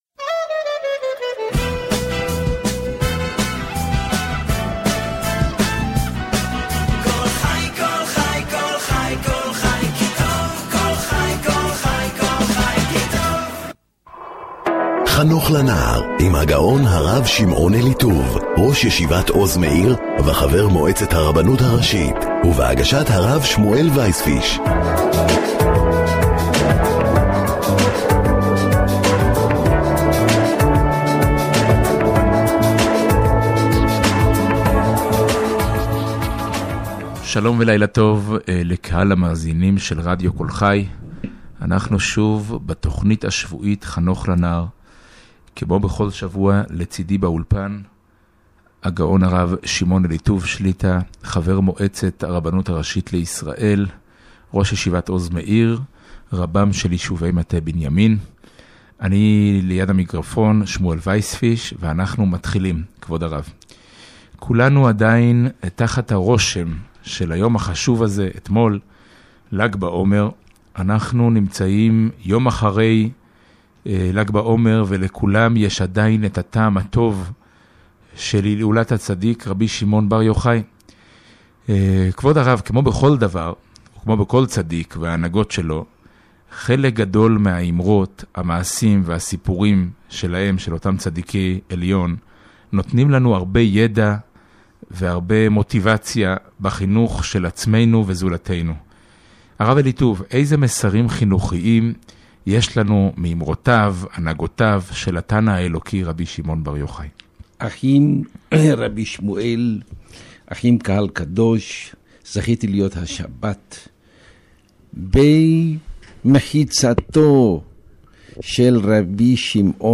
תוכנית מומלצת לכל הורה